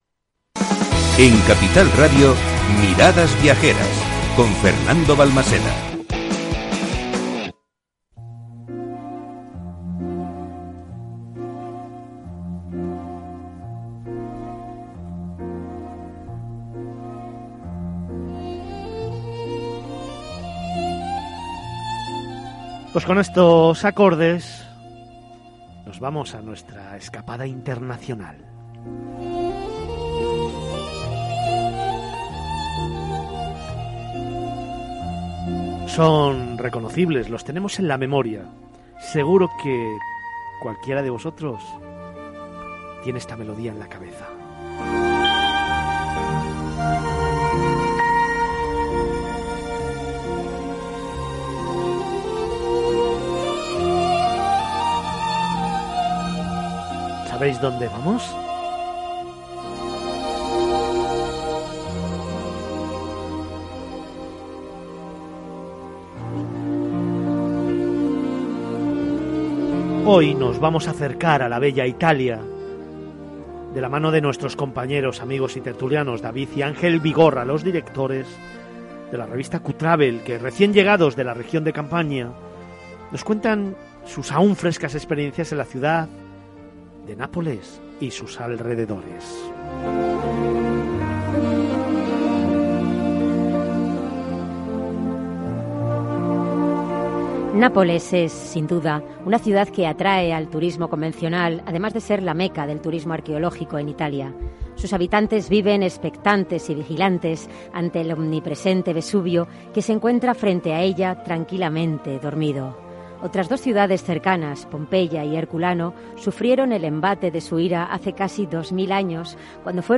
nos cuentan en el programa Miradas Viajeras en Capital Radio, aún frescas sus experiencias en la ciudad de Nápoles y alrededores.